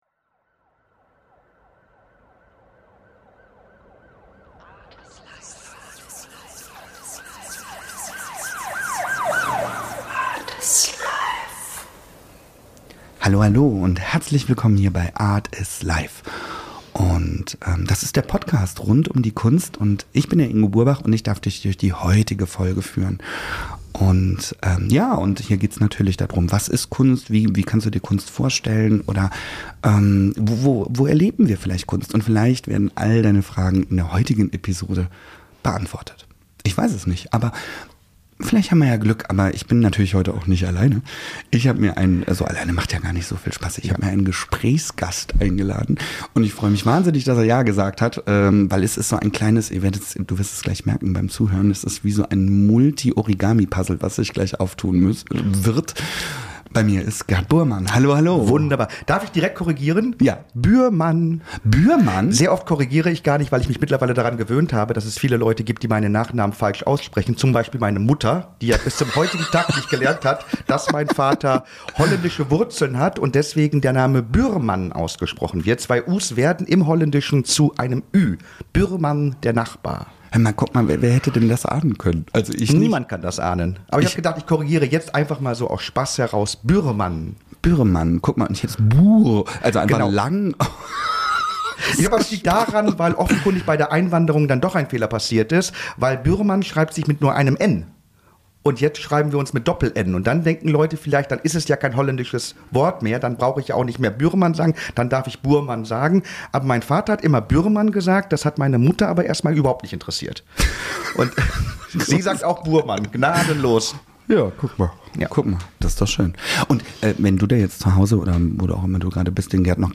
Eine temporeiches Interview einmal durch die Zeit und die Kunst.